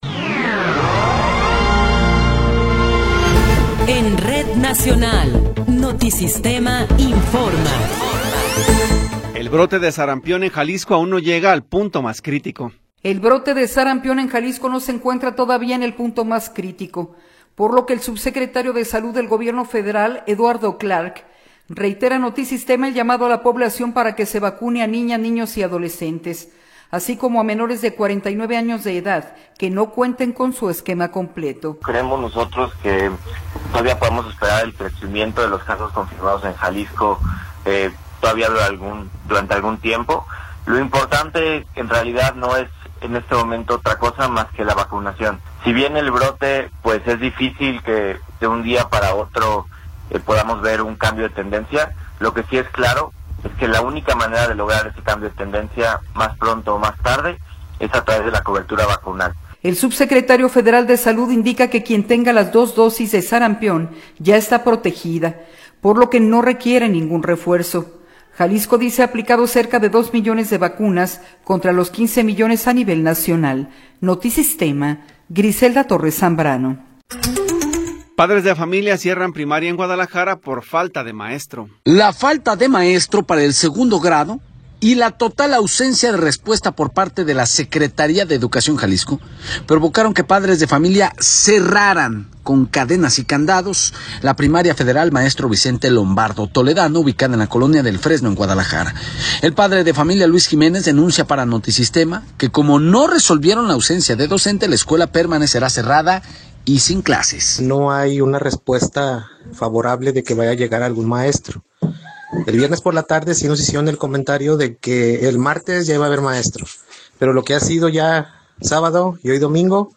Noticiero 11 hrs. – 16 de Febrero de 2026